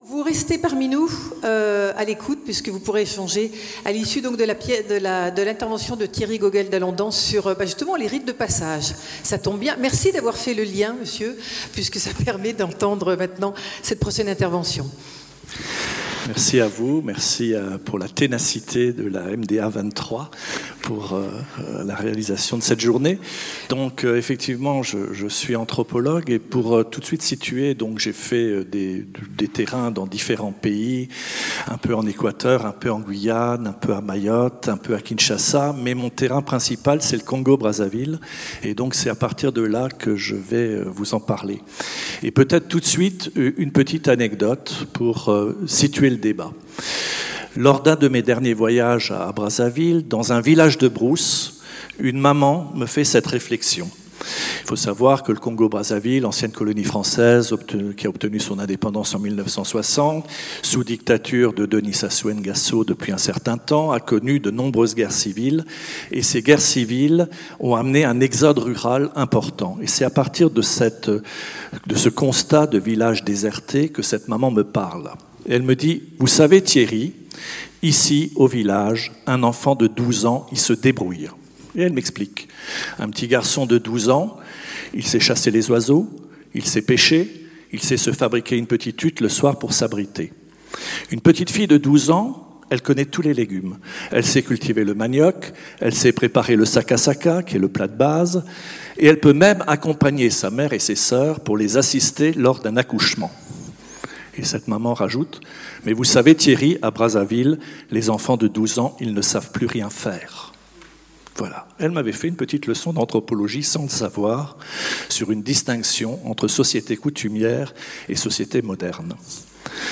MDA – Colloque du 22 juin 2022 « La Demande Adolescente »